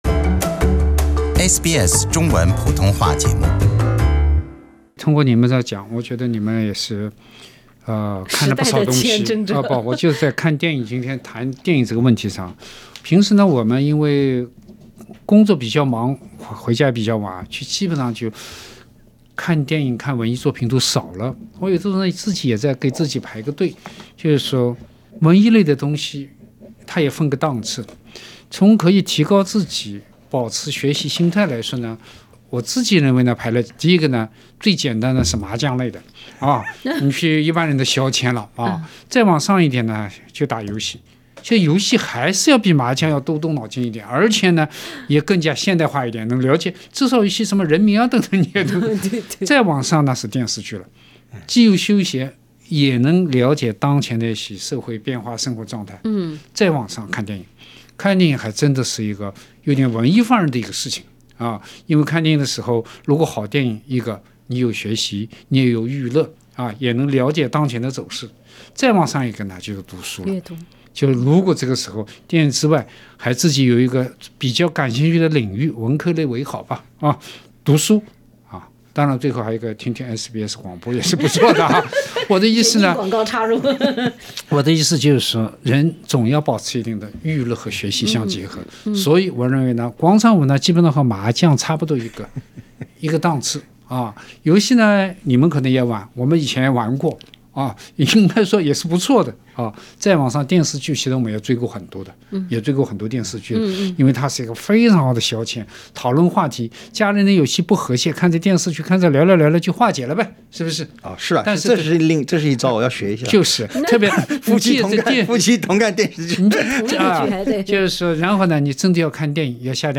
爱看露天电影的人总有几分浪漫，尤其是在从前的日子里。《文化苦丁茶》三人组在温馨回想往日各自美好且独特的经历。